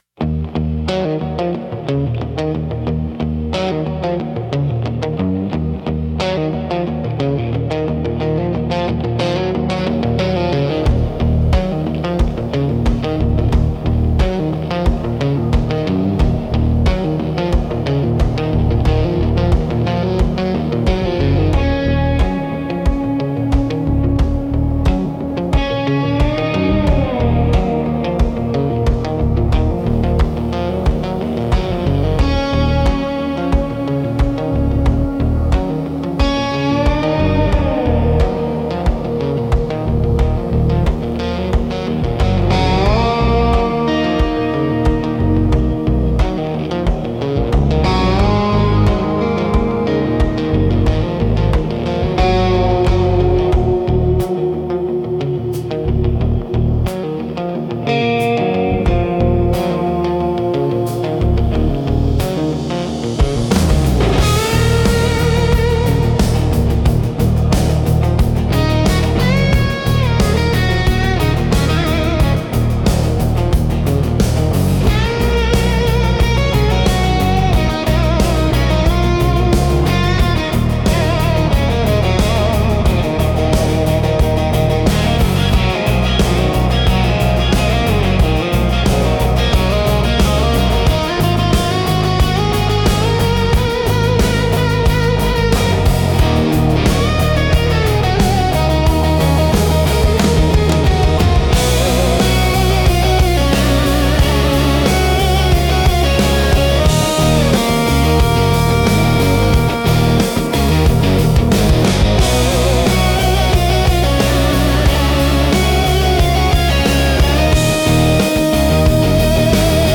Instrumental - Gospel of the Ghost Town 3.11